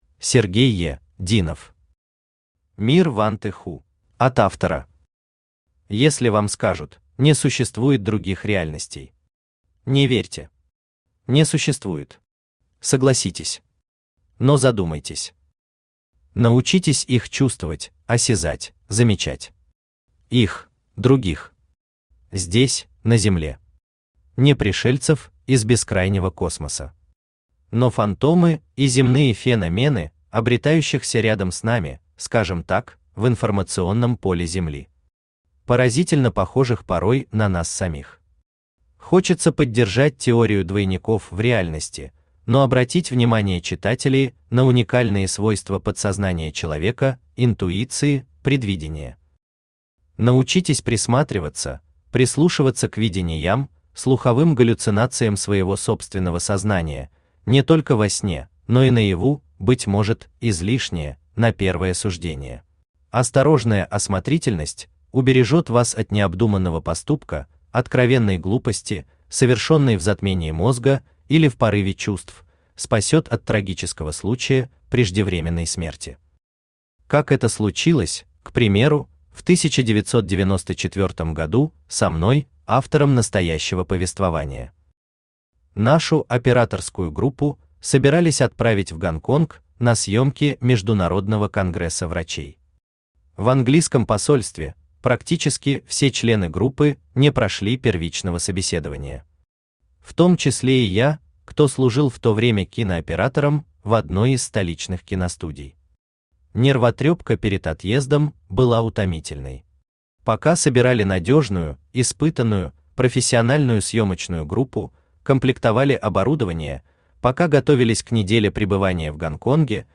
Аудиокнига Мир Ванты Ху | Библиотека аудиокниг
Aудиокнига Мир Ванты Ху Автор Сергей Е.ДИНОВ Читает аудиокнигу Авточтец ЛитРес.